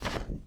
Vault_Deposit.wav